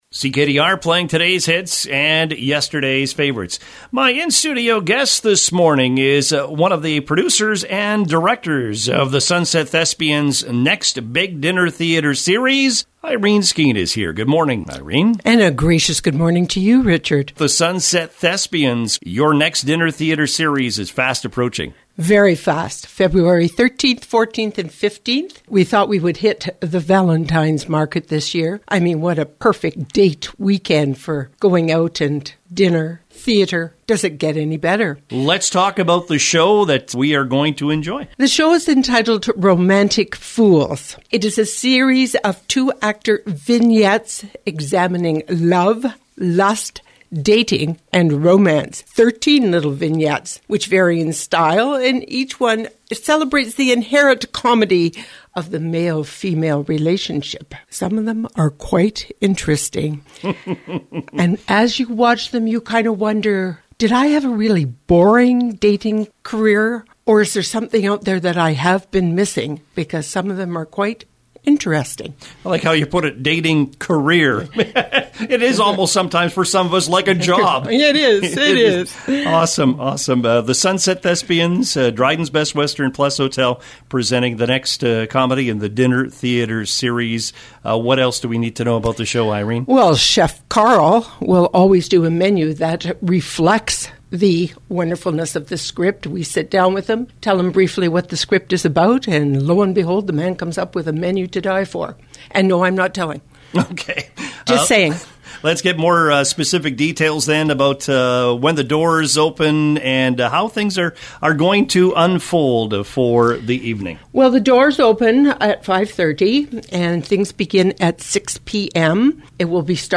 A perfect Valentines Day show is coming to Dryden next month.